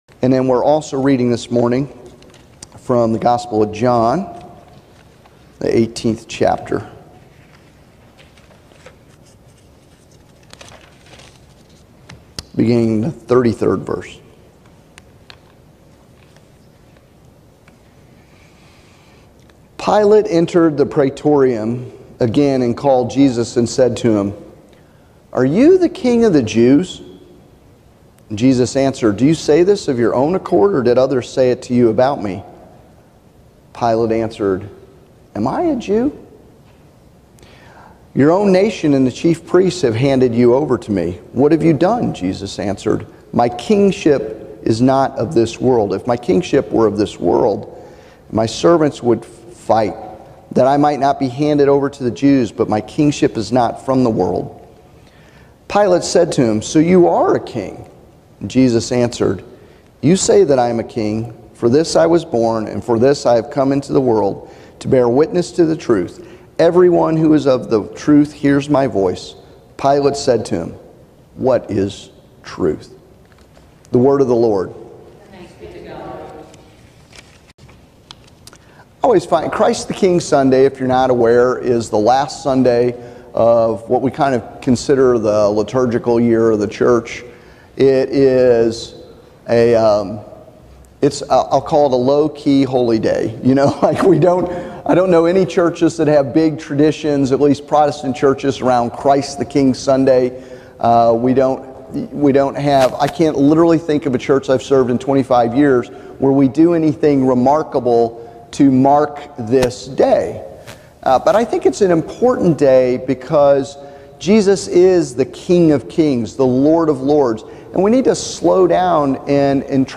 Listen to more great sermons on our sermon archive page , or better yet come join us for worship any Sunday at 11 a.m. Find out what to expect on your first visit by watching our welcome video on our “start here page.”